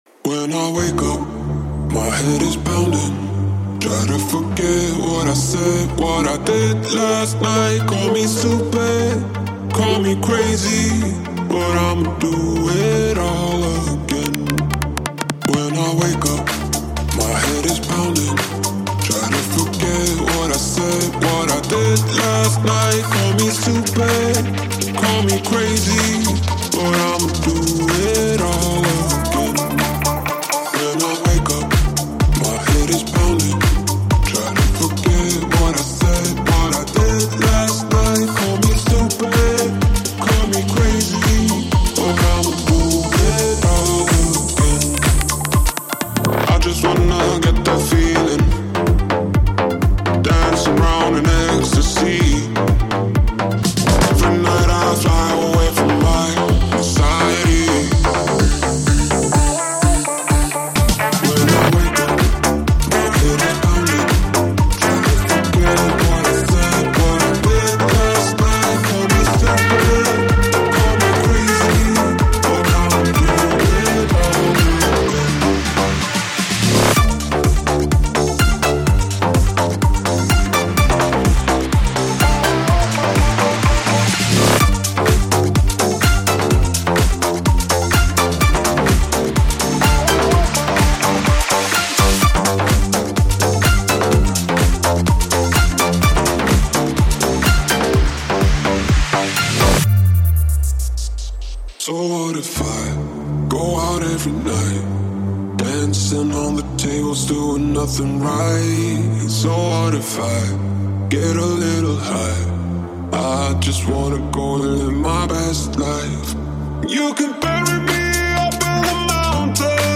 High quality Sri Lankan remix MP3 (3.1).